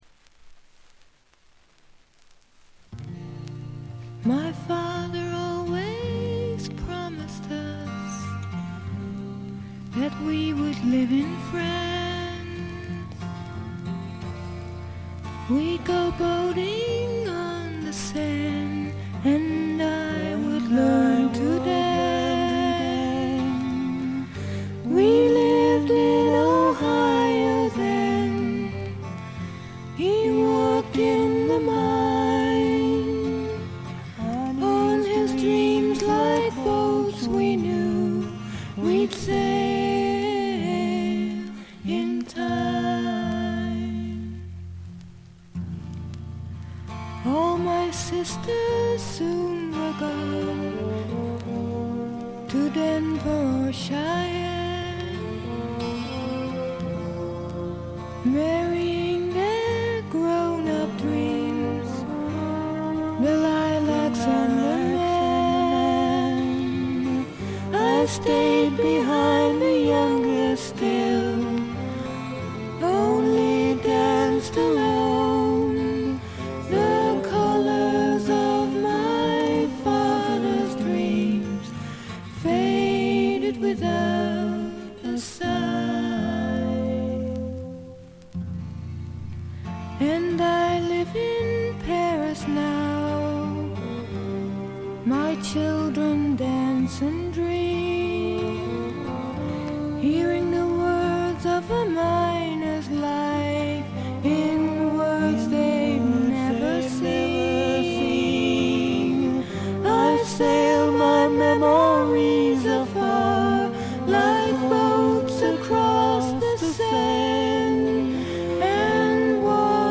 静音部で低いバックグラウンドノイズ。目立つノイズはありません。
試聴曲は現品からの取り込み音源です。